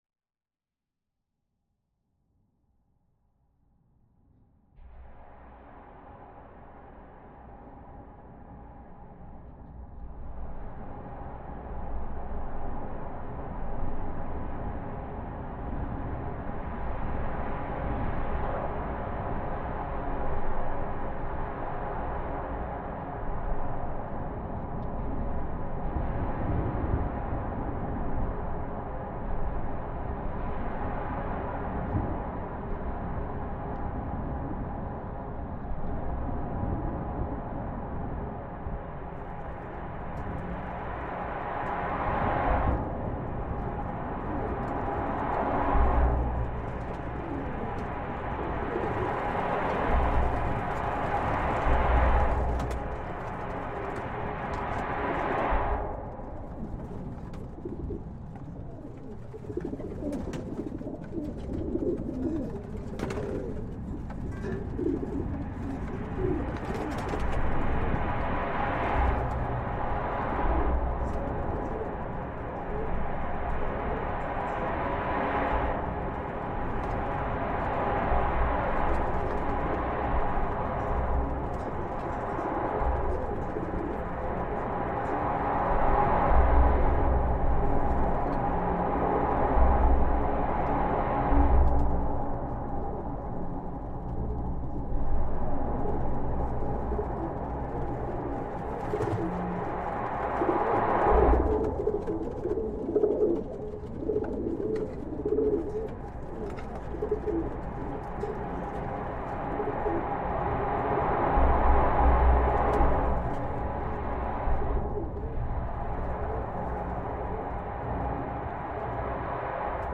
In this recording, the mechanical vibrations of Rotterdam’s iconic bridge,echoing the city’s maritime heritage and constant motion—blend with the bustling acoustics of the surrounding waterfront. The rhythmic pulse of passing ships and the distant hum of urban life intermingle with the low drone of the bridge’s support cables.
A unique environment with strong sonic signatures, the waterfront envelops you in a sense of deep listening through layers of time, even as the ever-present vibration of the bridge grounds you in the here and now. To capture this immersive soundscape, I used hydrophones to record underwater currents and hidden microbial activity, geophones to sense the bridge’s low-frequency vibrations, and contact mics affixed directly to its support structures. Sensitive stereo microphones were also employed to capture the ambient clamour of the city, from seagulls circling overhead to distant horns echoing across the water.